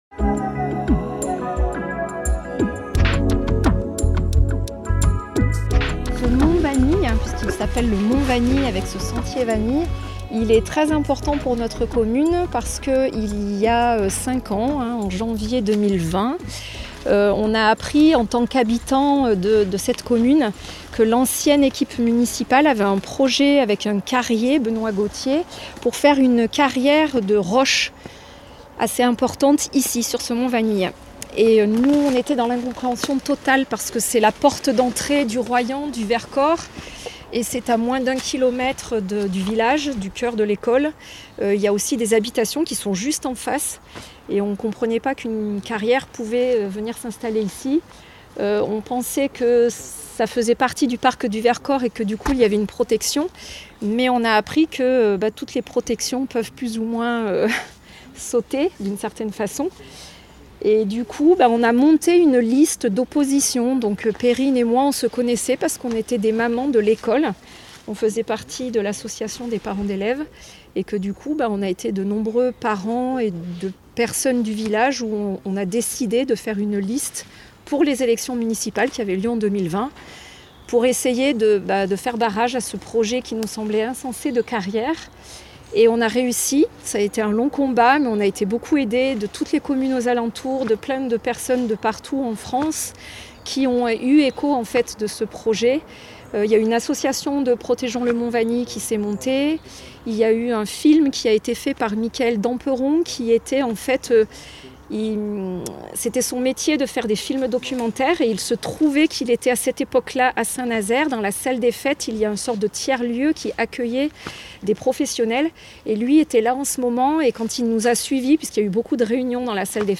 Pour un écho de la visite finale avec le collectif et les partenaires, captations et interview à chaud (oui, ça monte!).